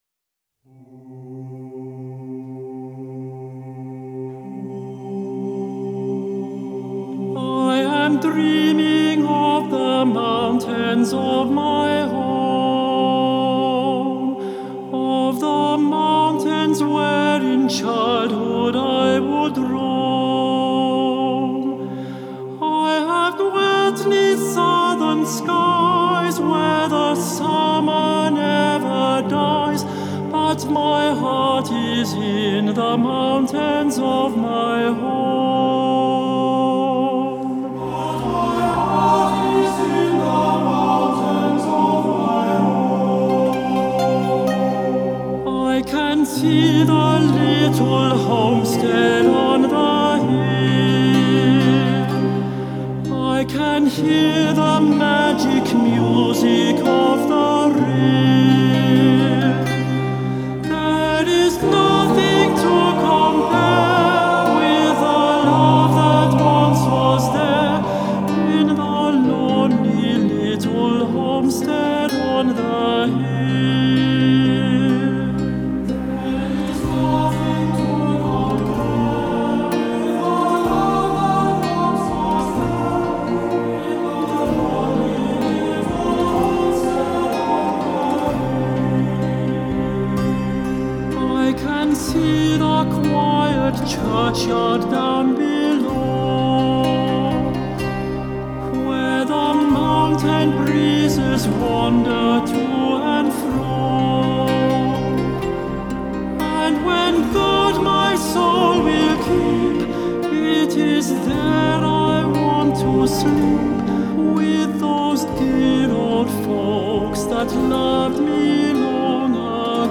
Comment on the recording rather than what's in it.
Hi-Res Stereo